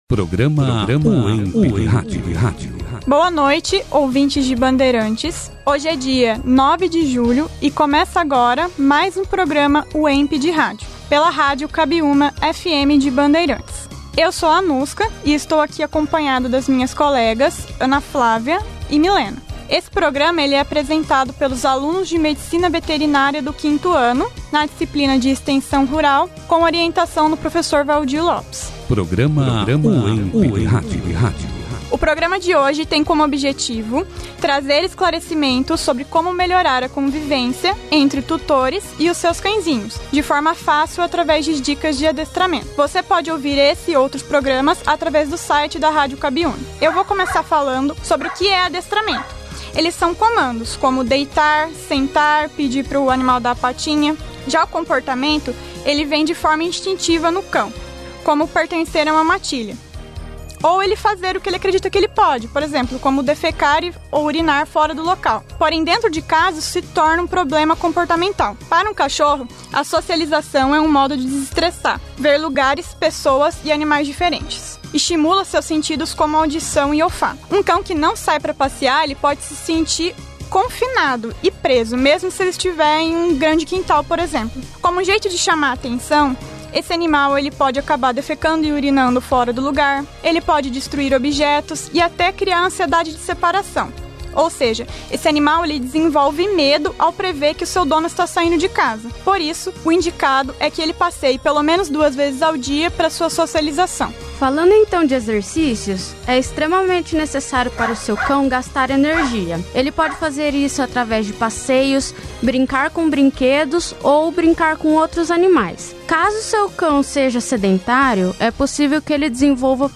Produzido e apresentado pelos alunos, Acadêmicos do 5º ano do curso de Medicina Veterinária.